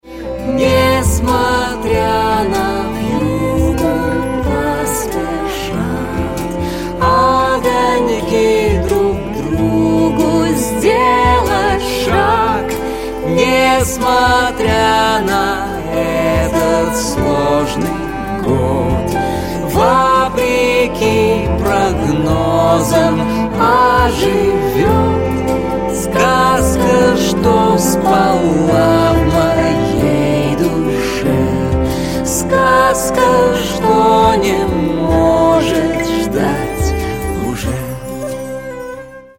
# Новогодние Рингтоны
# Поп Рингтоны